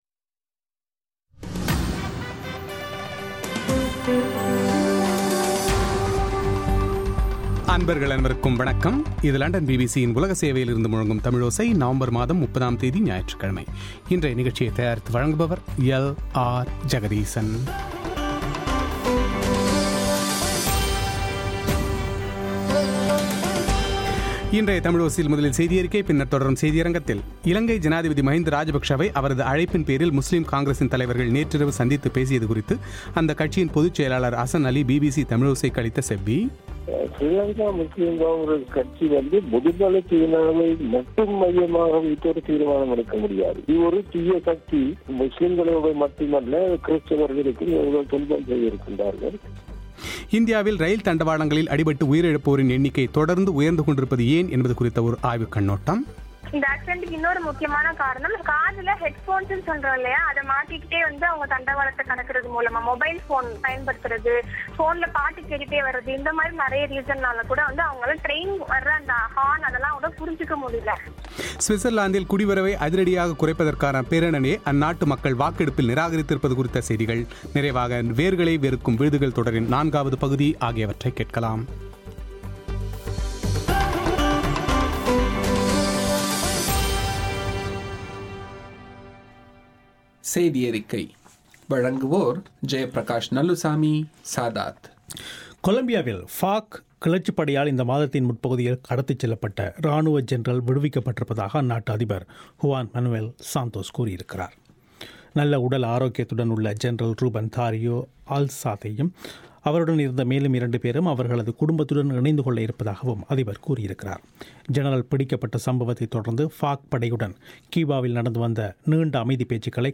இலங்கை ஜனாதிபதி மகிந்த ராஜபக்ஷவை அவரது அழைப்பின்பேரில், முஸ்லிம் காங்கிரஸின் தலைவர்கள் நேற்றிரவு சந்தித்துப் பேசியது குறித்து அக்கட்சியின் பொதுச் செயலாளர் ஹசன் அலி பிபிசி தமிழோசைக்கு அளித்த செவ்வி;